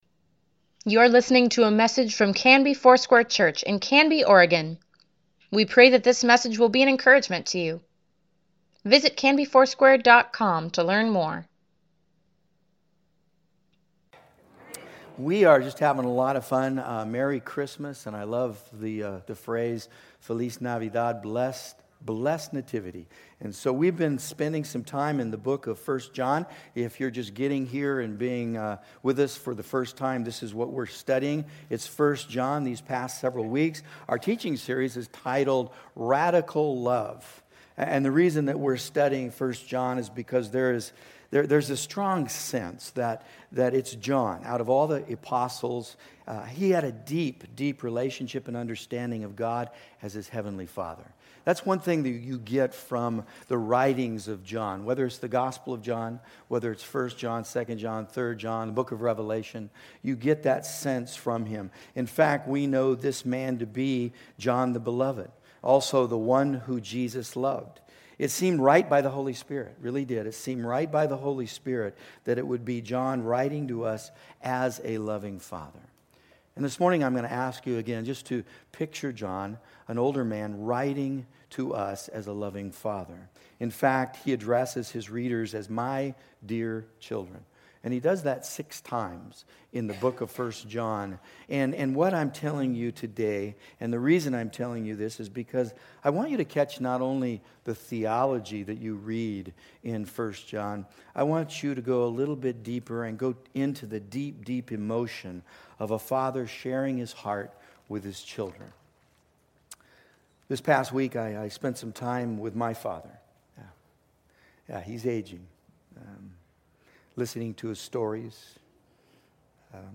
Weekly Email Water Baptism Prayer Events Sermons Give Care for Carus 1 John, pt. 5 December 8, 2019